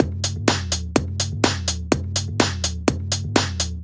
Techno snare drum Free sound effects and audio clips
Original creative-commons licensed sounds for DJ's and music producers, recorded with high quality studio microphones.
future_novelty_snare_and_synth_techno_loop_bia.wav